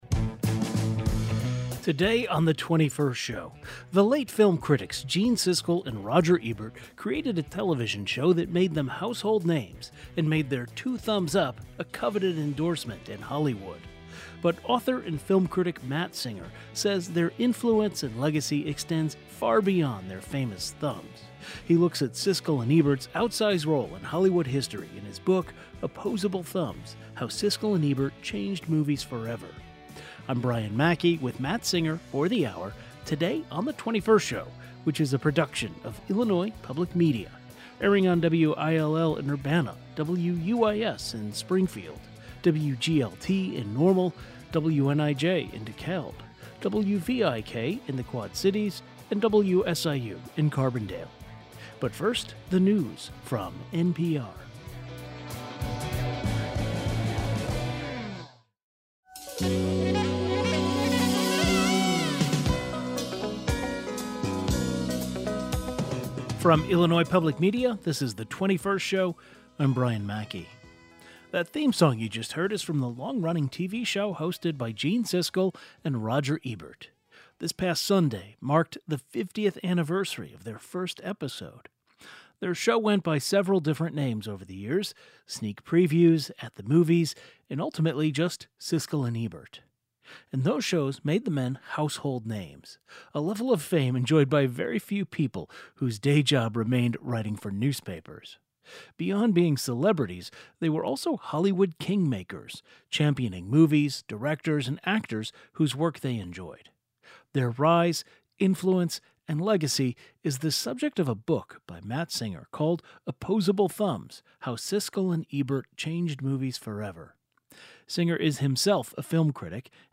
Today's show included a rebroadcast of the following "best of" segment, first aired November 20, 2023: